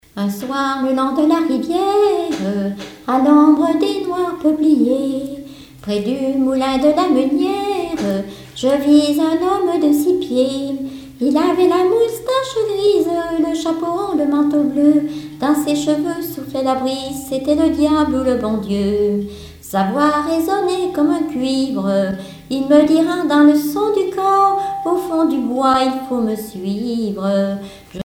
chansons et témoignages parlés
Pièce musicale inédite